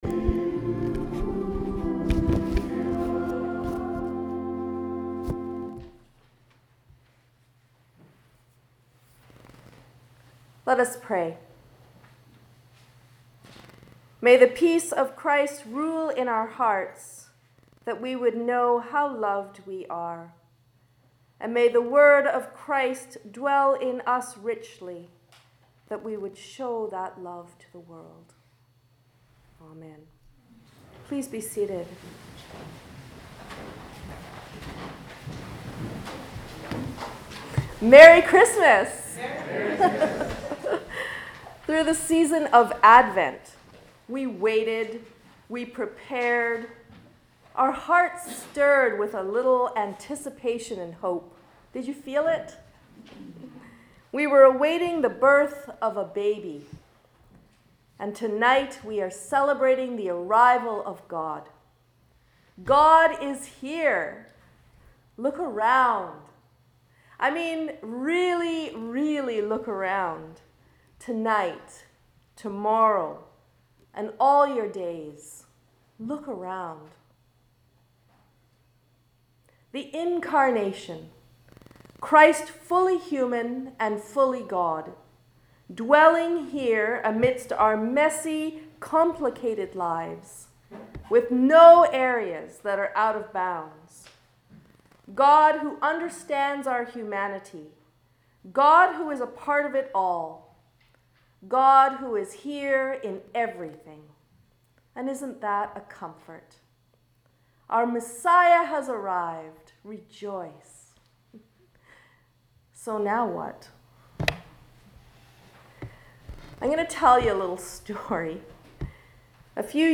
Sermons | St. Marys' Metchosin
11pm service at St Mary's the Virgin (Heritage Church)